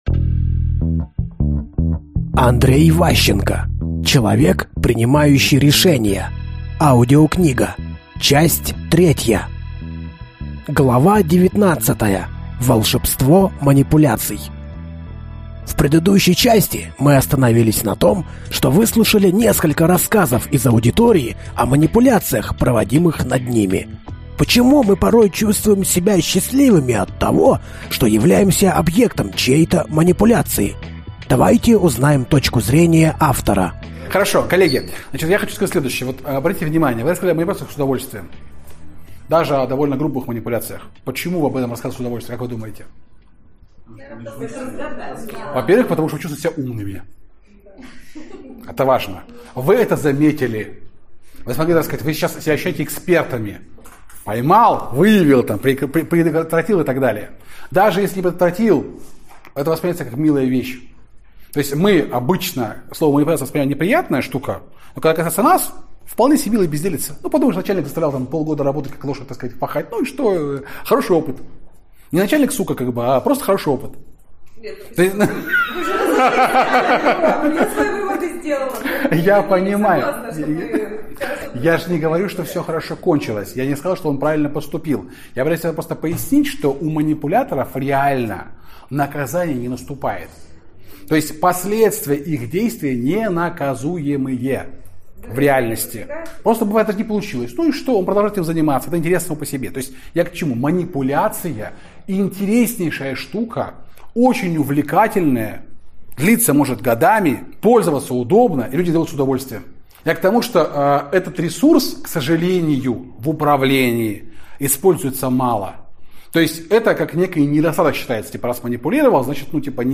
Аудиокнига Человек, принимающий решения. Часть 3 | Библиотека аудиокниг